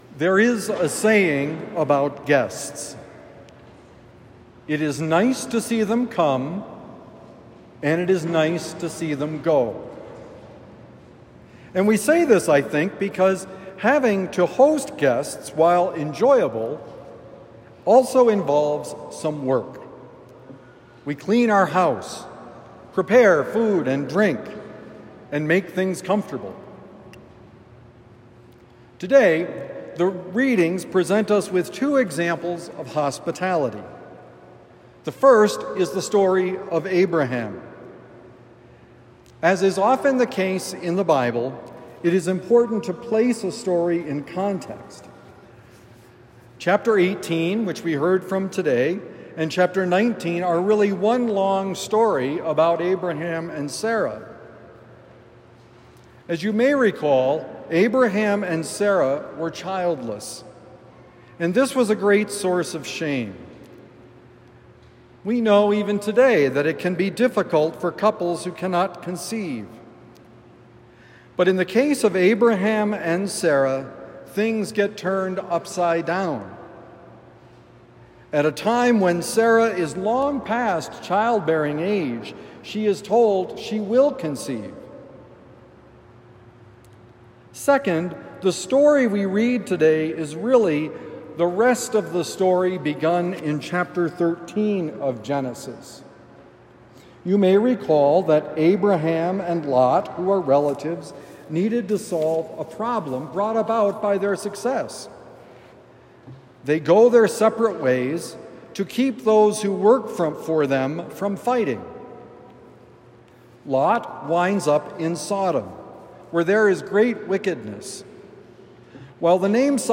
Actions that arise from prayer: Homily for Sunday, July 20, 2025